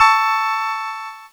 Cheese Chord 15-A3.wav